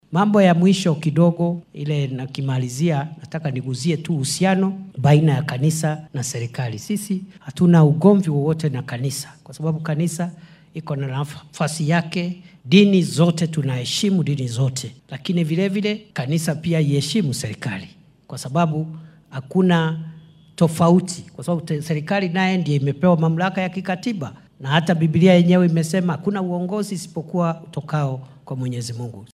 Kindiki ayaa xilli uu ku sugnaa deegaanka Navakholo ee ismaamulka Kakamega sheegay in ay lagama maarmaan tahay in dhaliilaha dowladda loo jeedinaya ay noqdaan kuwo xaqiiqada ku saleysan .